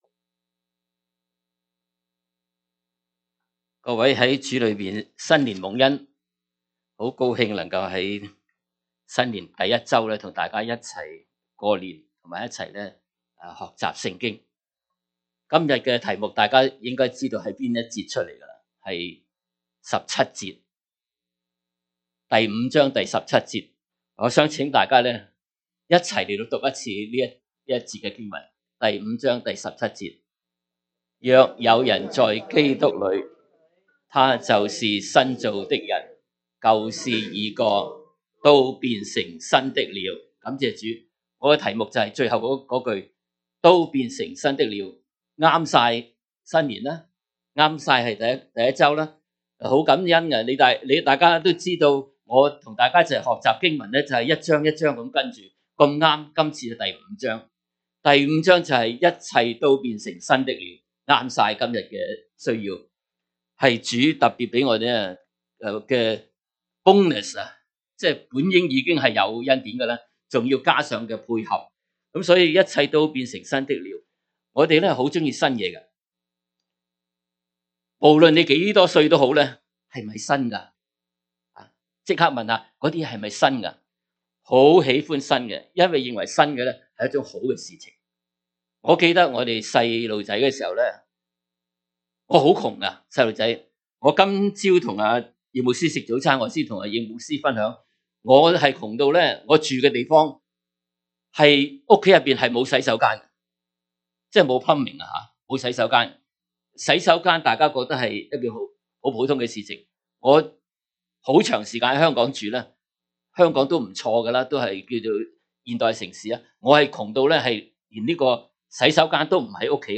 Sermons by CCCI